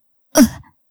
战斗-受伤.wav